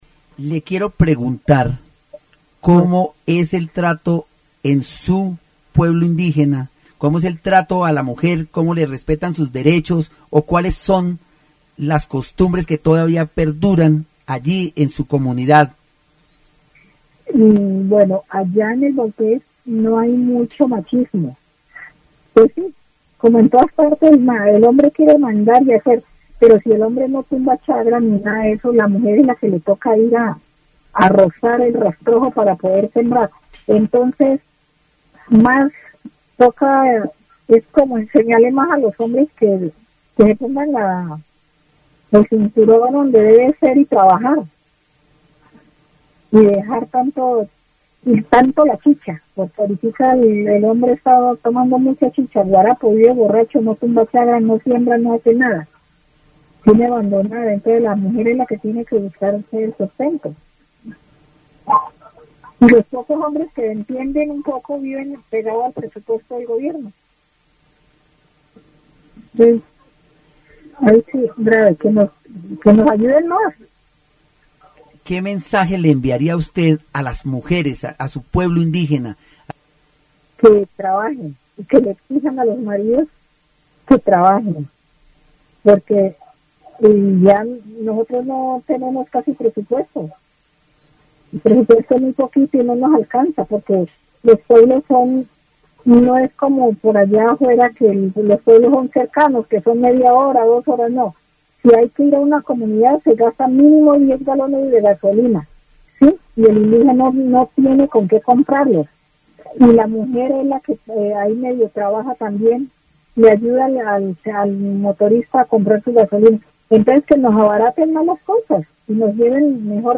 Women , Machismo , Indigenous community , Work , Voices and Regions , Vaupés (Región, Colombia) -- Grabaciones sonoras , Programas de radio